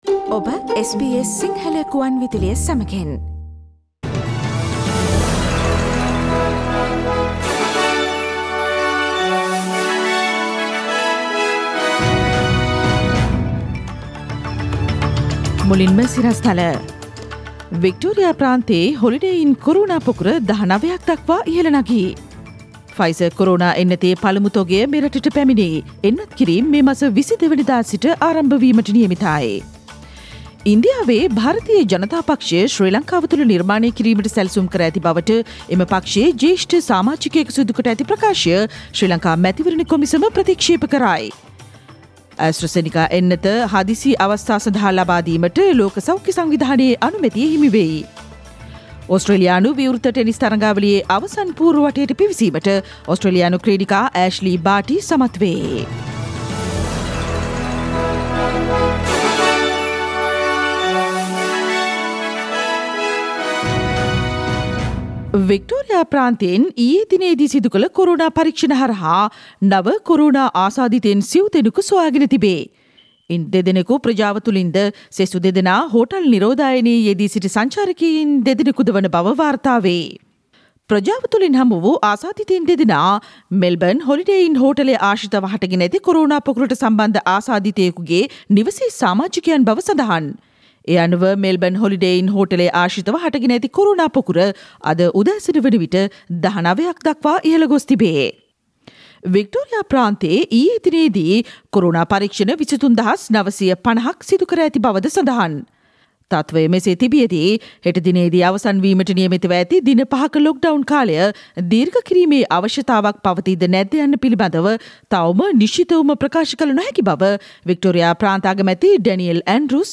SBS Sinhala radio news on 16 February 2021.